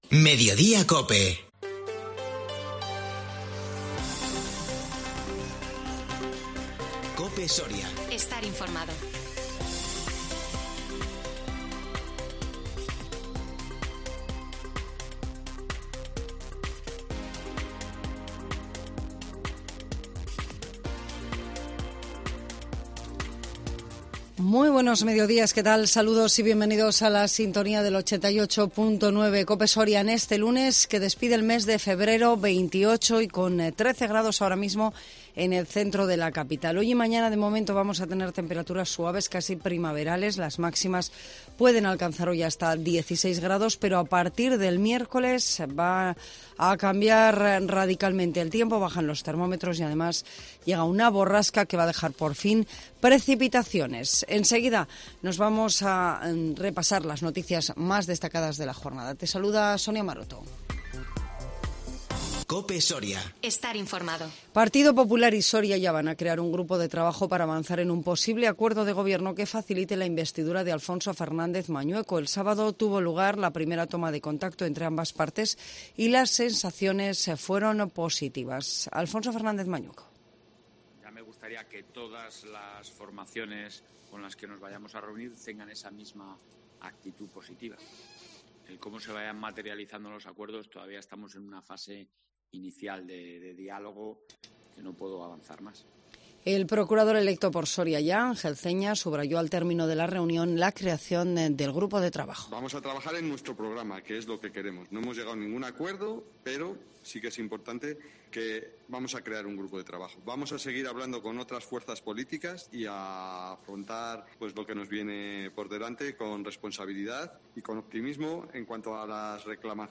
INFORMATIVO MEDIODÍA COPE SORIA 28 FEBRERO